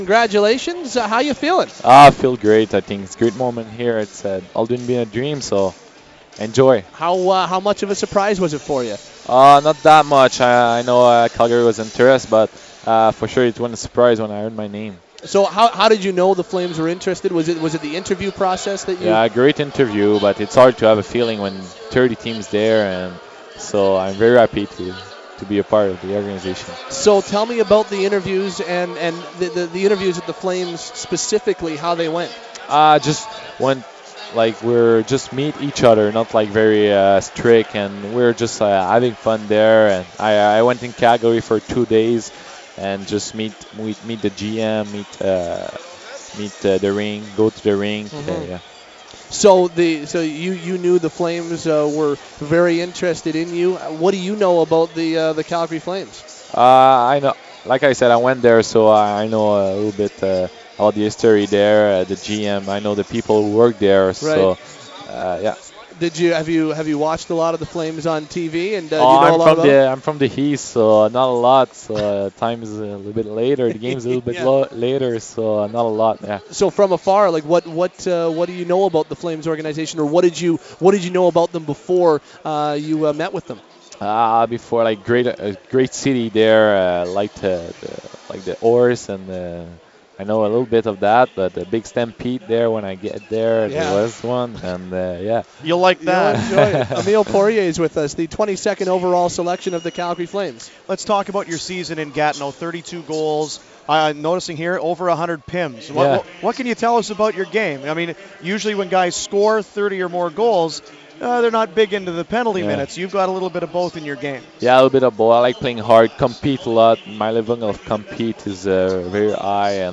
He confirms in the 1st minute of his draft day interview that he went to Calgary pre-draft.